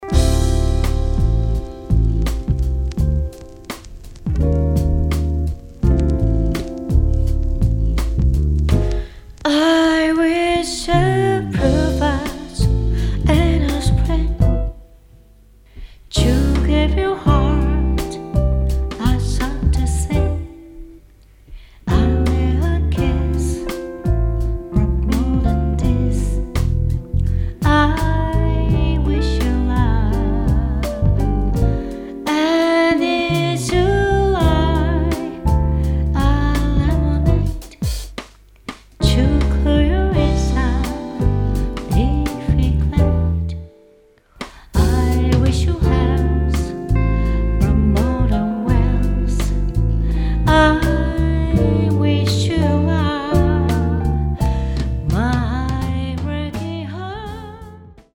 ボーカリスト担当